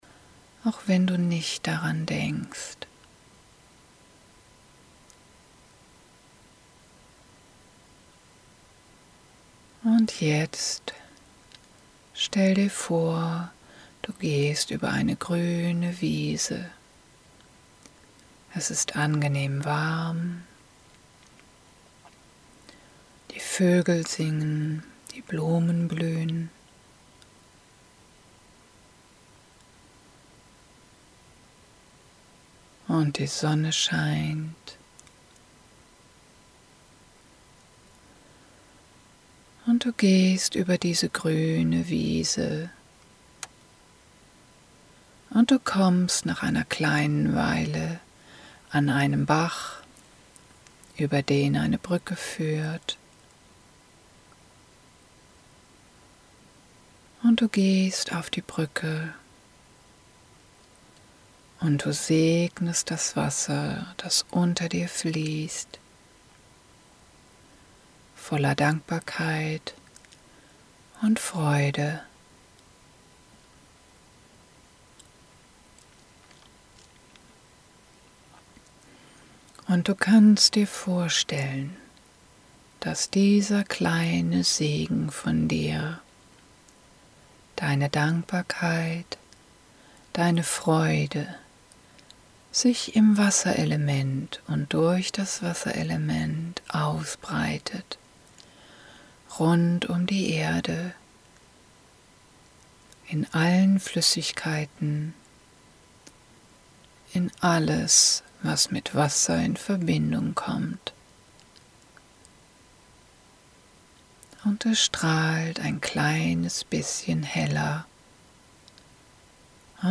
Eine Meditation